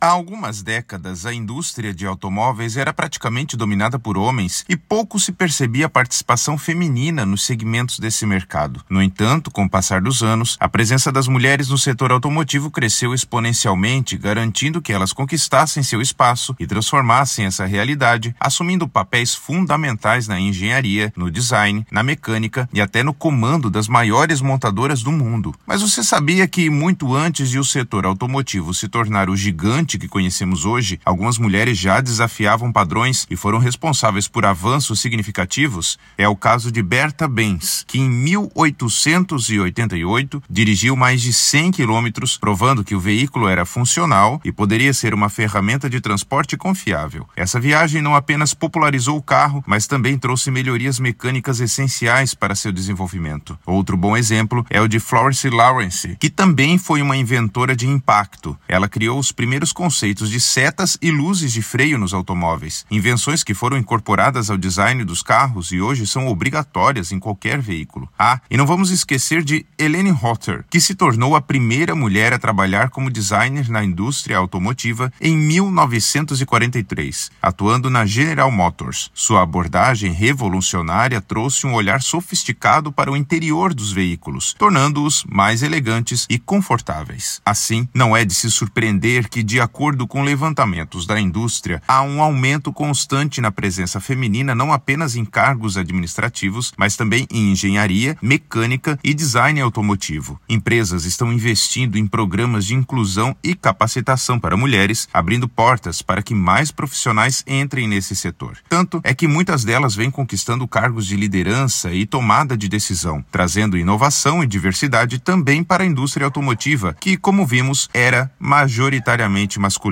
Narracao-01-mulheres-no-setor-automotivo.mp3